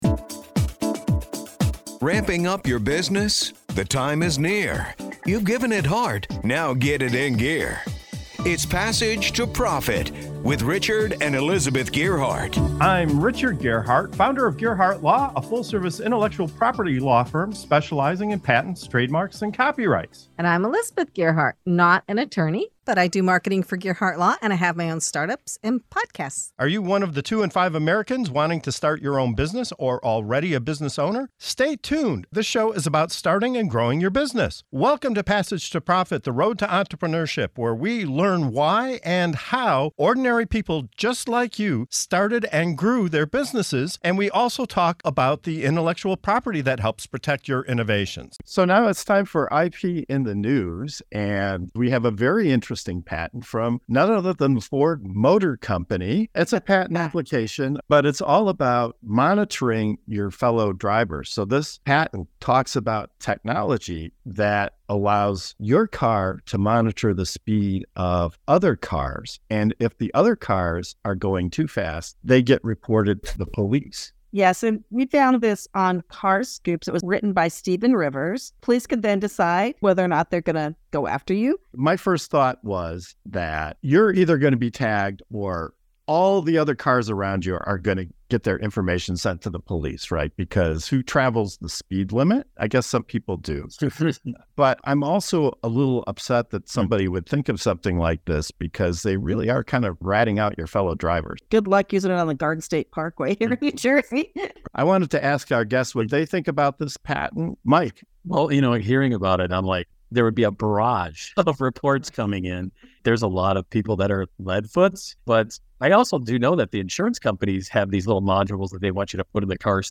In this segment of IP in the News on the Passage to Profit Show, we dive into a controversial new patent from Ford Motor Company that proposes monitoring the speed of other cars and reporting speeding drivers to the police. Opinions fly as they discuss the potential privacy concerns, the role of technology in public safety, and how this could turn everyday drivers into road surveillance agents. From New Jersey highways to the ethics of data collection, this conversation covers the fine line between innovation and overreach.